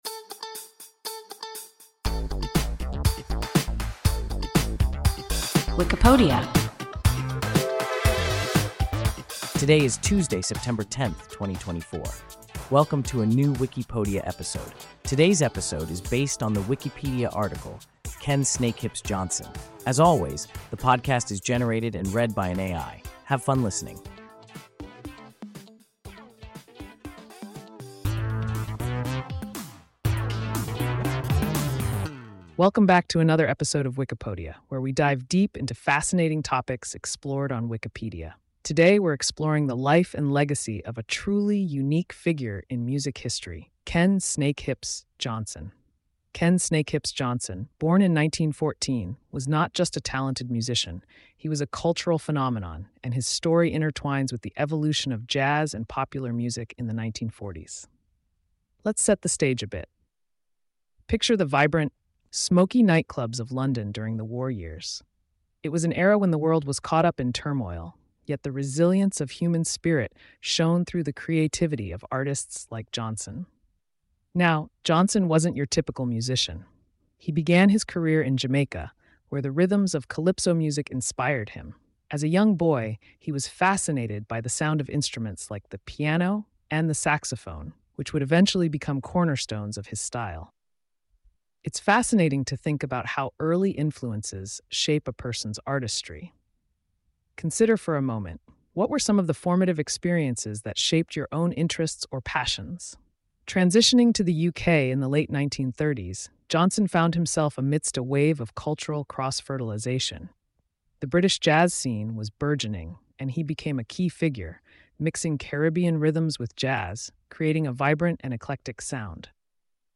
Ken Snakehips Johnson – WIKIPODIA – ein KI Podcast